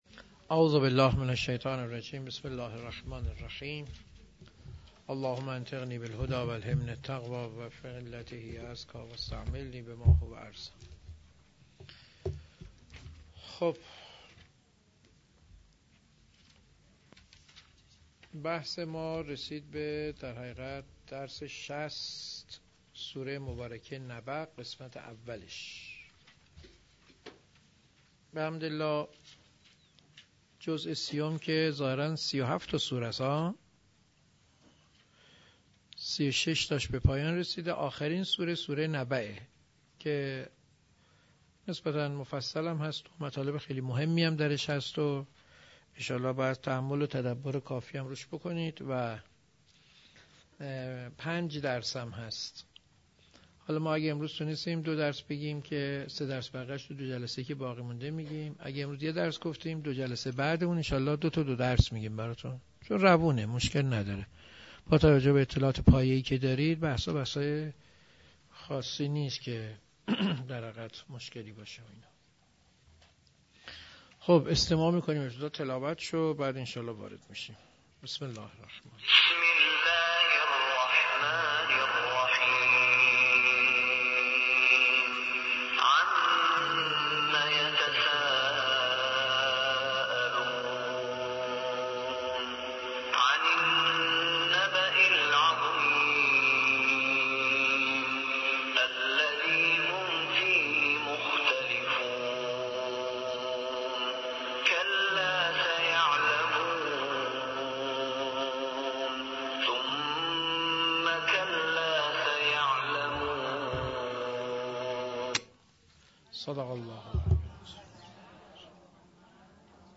مفاهیم قرآن - جلسه 40